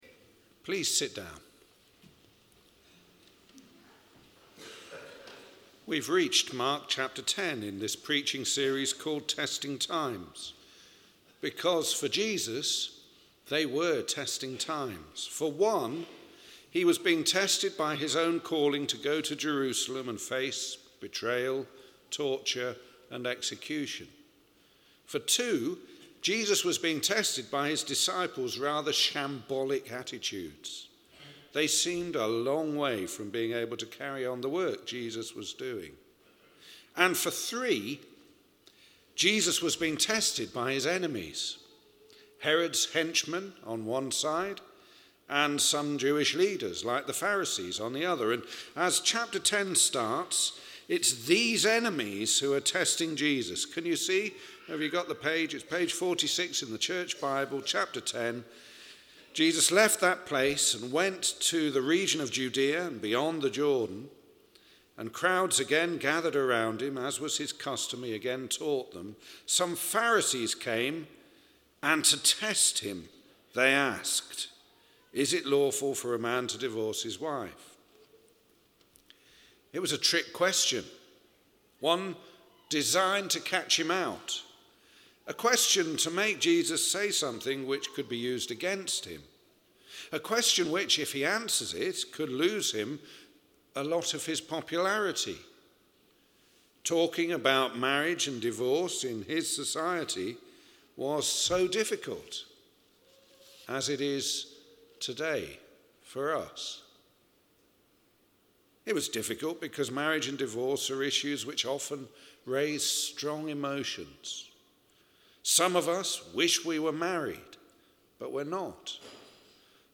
Home Events and News St Martin's Services Sermons About Us Contact Links Groups and Events Testing Times From Mark 9 —10 Jesus sets his sights on Jerusalem. This series is called "Testing Times" because Jesus' enemies (and others) were giving him an increasingly hard time, including questions which would compromise his popularity and others which might be used against him as future charges.